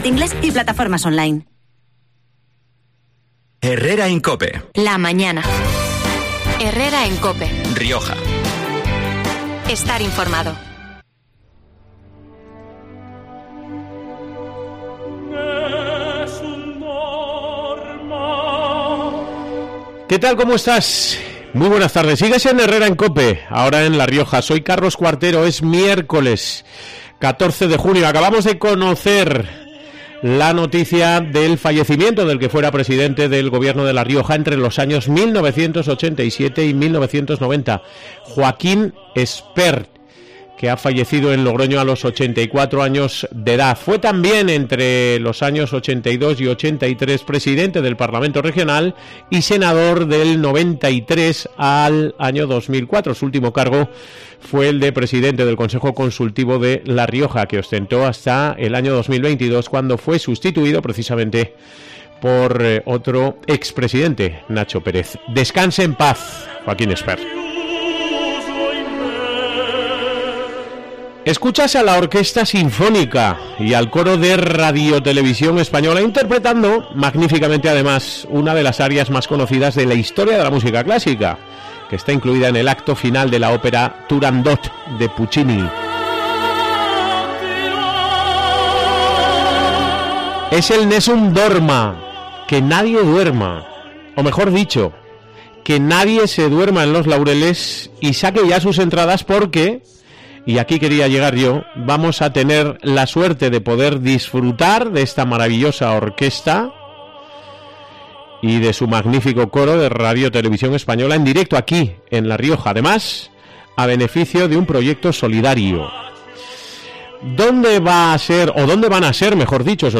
durante la emisión del 'Fin de Semana' de COPE.